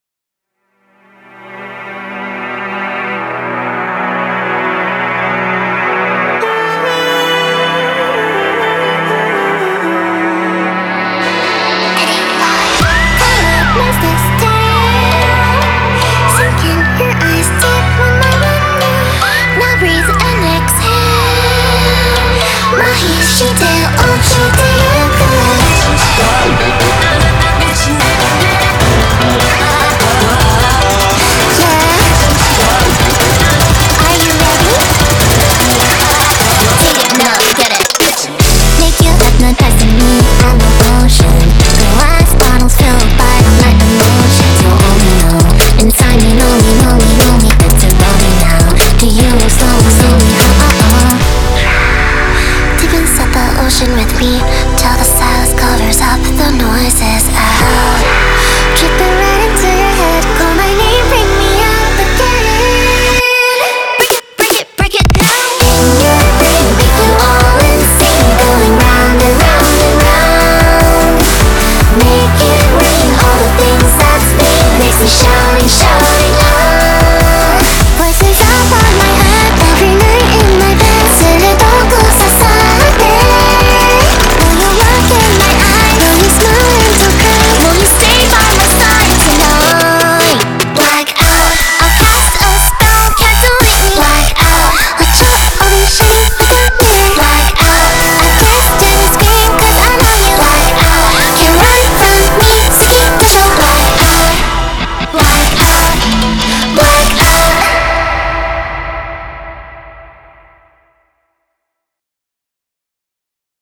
BPM150
MP3 QualityMusic Cut